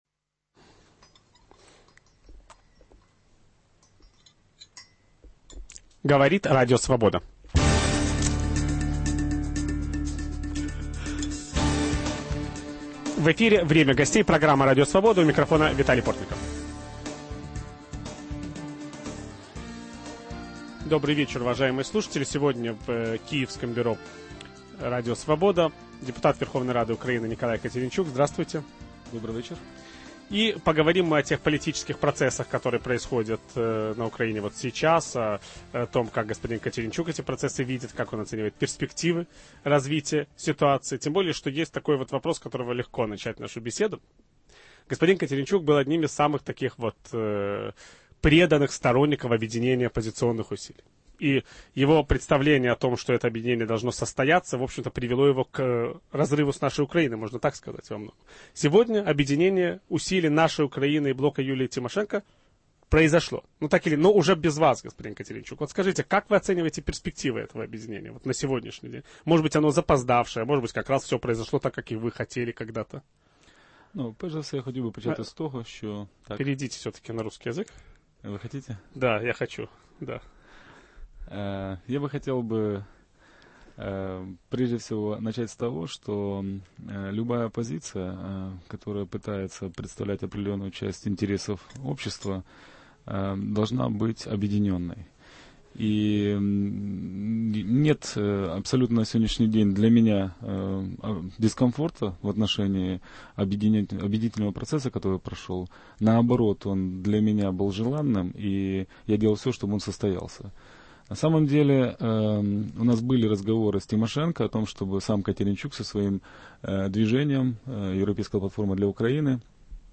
О политических процессах на Украине ведущий программы Виталий Портников беседует с народным депутатом Украины Николаем Катеринчуком.